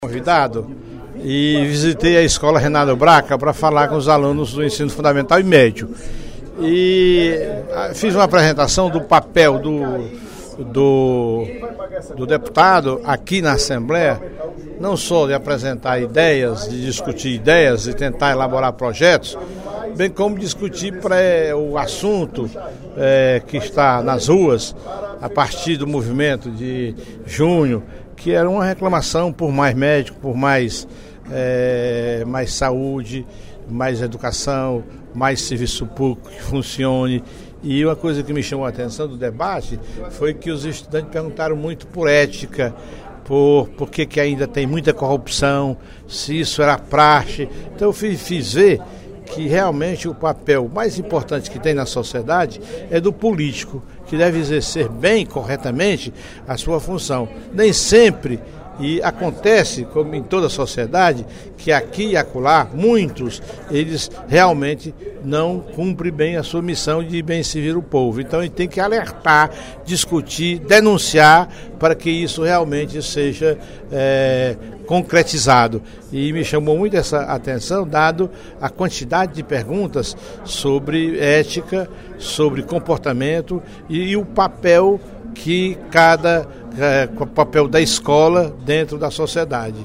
O deputado Professor Teodoro (PSD) chamou atenção, nesta quinta-feira (24/10), no primeiro expediente da sessão plenária, para a responsabilidade dos parlamentares com a sociedade. O parlamentar lamentou, entretanto, o artigo 60 da Constituição Federal que, segundo ele, pode tolher o poder de atuação dos parlamentares.